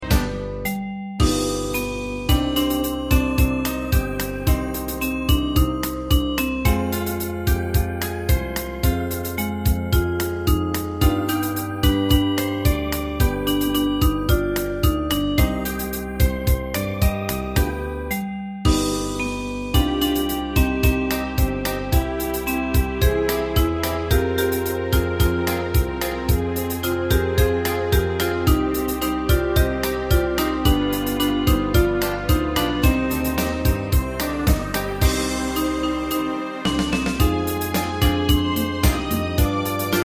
大正琴の「楽譜、練習用の音」データのセットをダウンロードで『すぐに』お届け！
カテゴリー: アンサンブル（合奏） .
歌謡曲・演歌